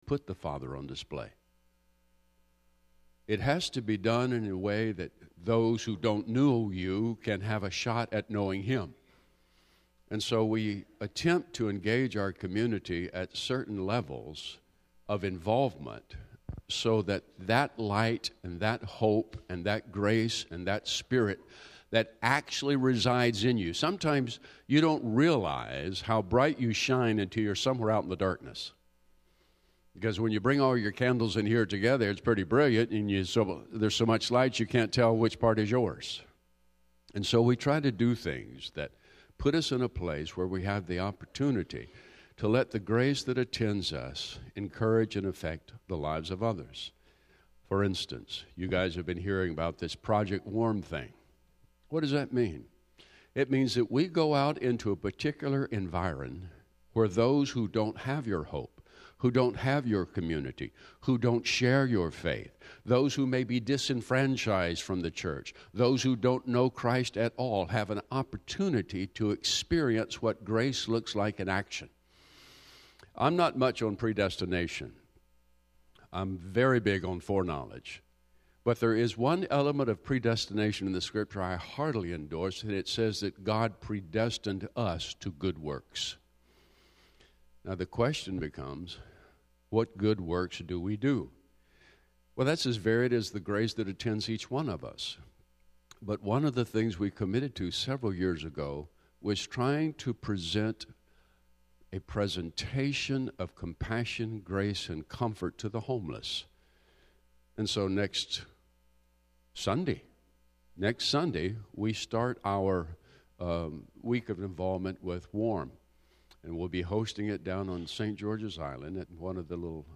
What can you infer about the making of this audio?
The message concludes with a special foot-washing ceremony.